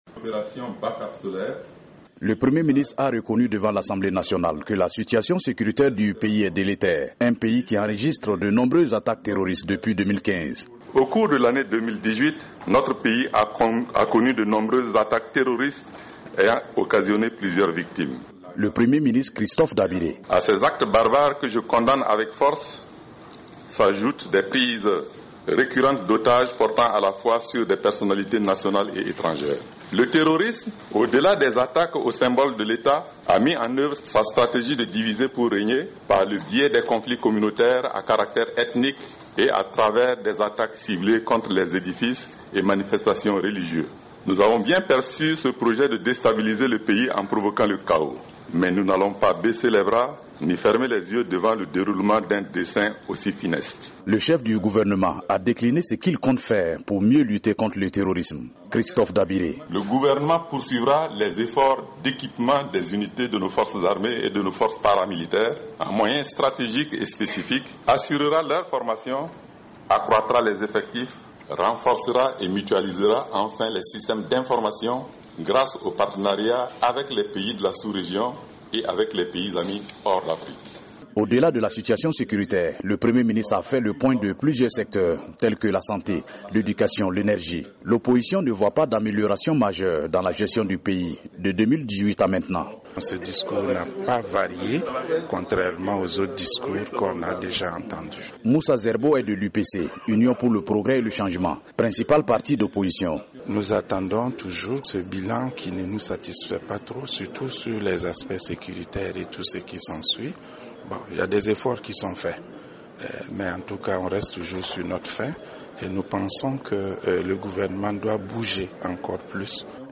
Le Premier ministre burkinabè Christophe Dabiré était face aux députés pour son premier discours sur la situation nationale. Un discours dans un contexte de recrudescence des attaques terroristes dans le nord. De Ouagadougou